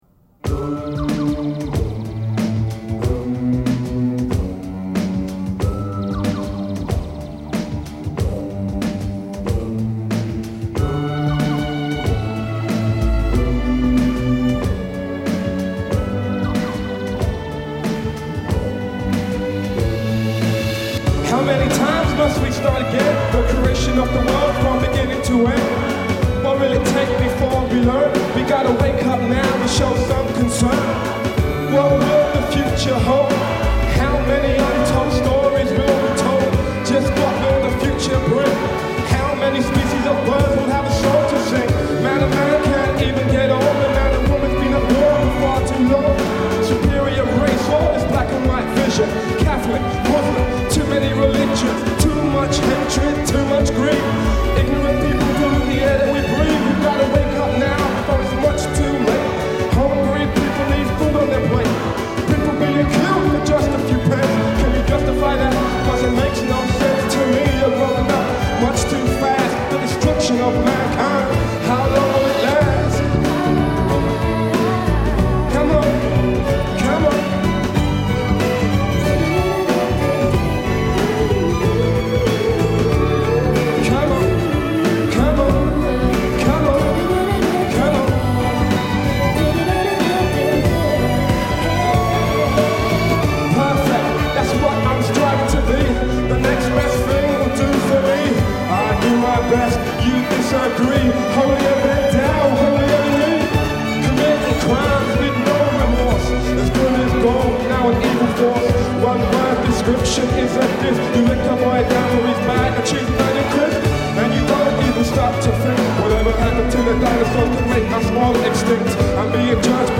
아래는 레게 팝버전
캐논변주곡-레게팝버젼.mp3